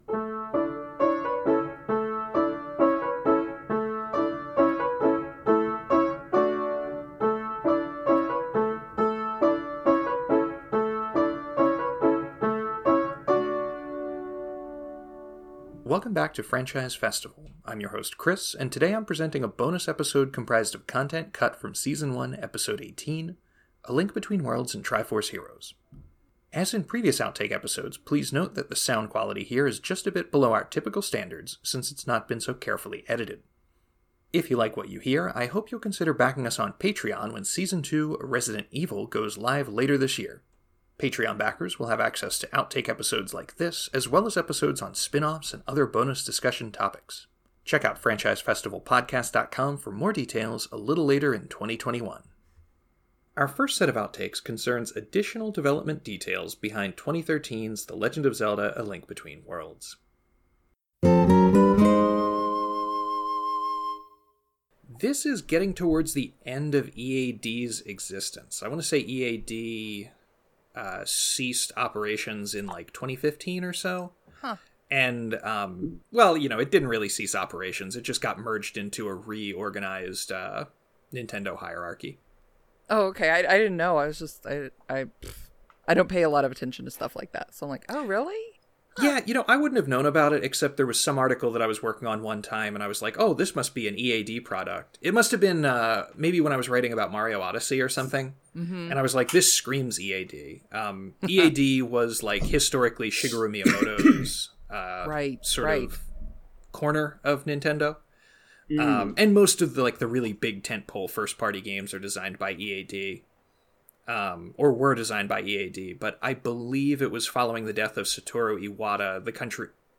Note that audio quality and editing may be a touch rougher than standard episodes.